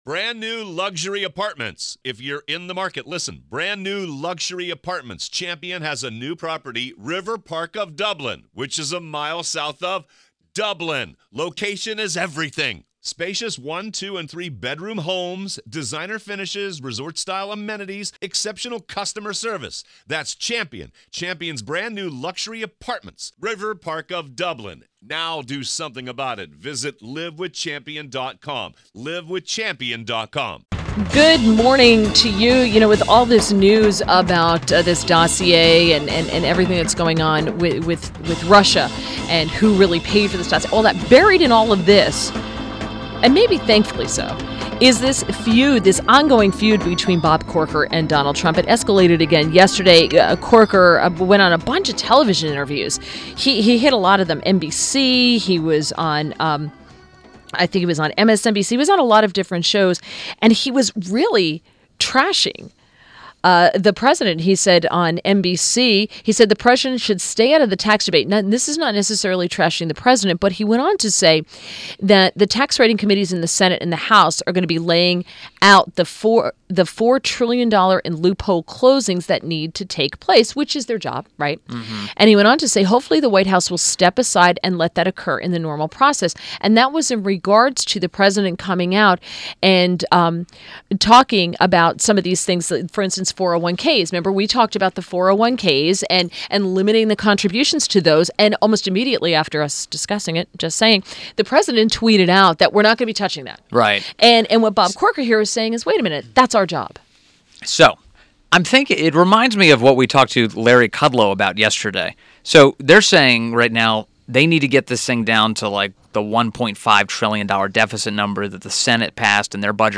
INTERVIEW - KEVIN CORKE - White House Correspondent, Fox News- WH news